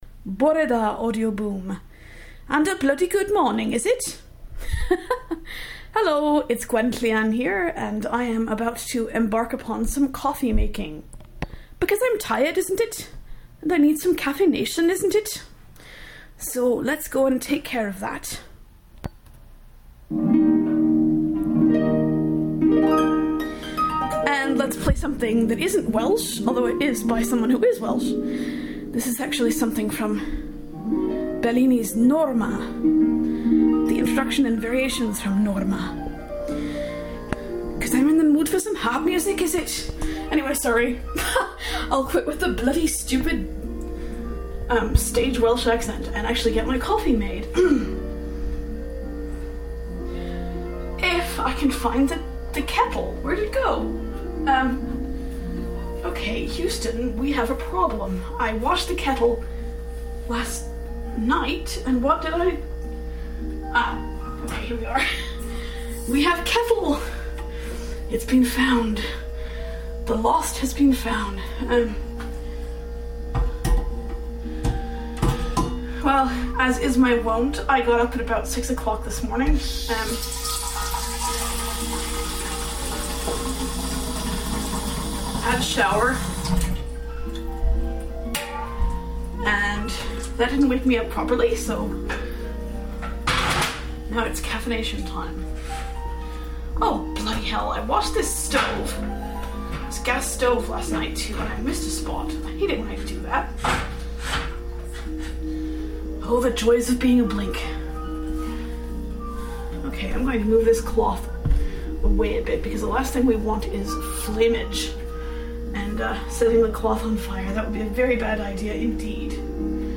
=P Sorry, forgot to mention in the Boo itself that the music in this post was played by Welsh harpist Catrin Finch.